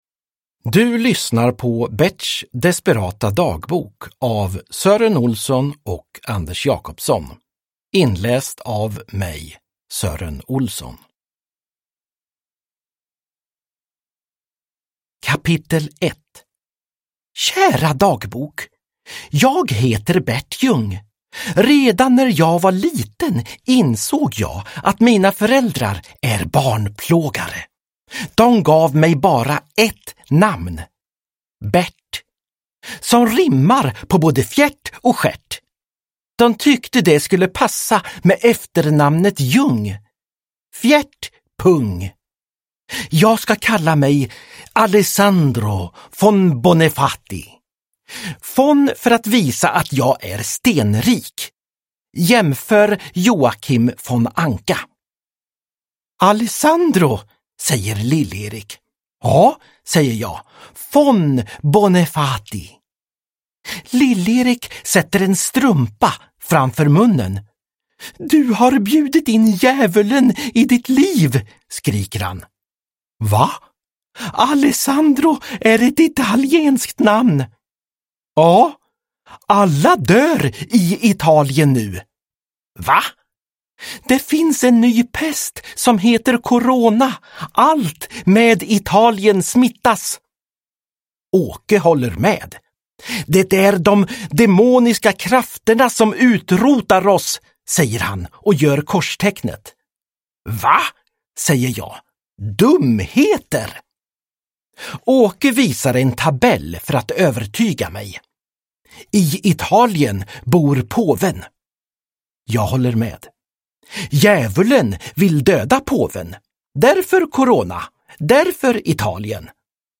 Berts desperata dagbok – Ljudbok – Laddas ner
Uppläsare: Sören Olsson